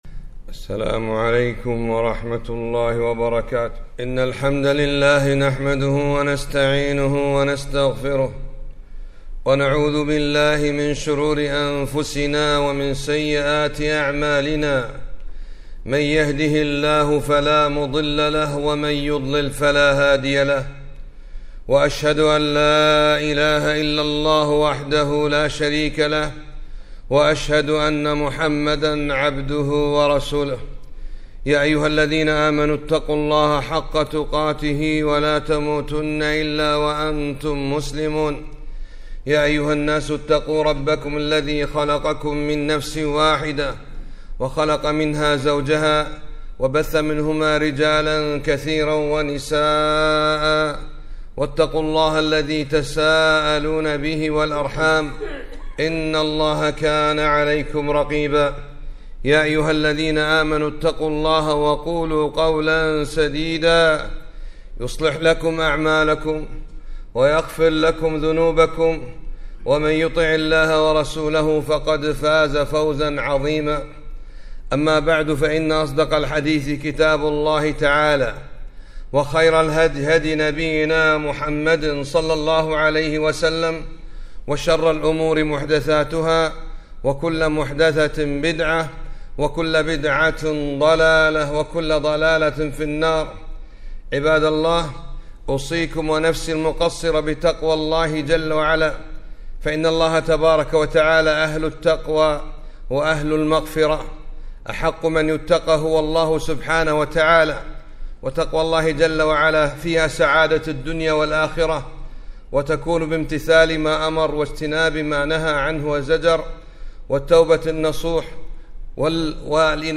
خطبة - صبر نوح عليه السلام في الدعوة إلى الله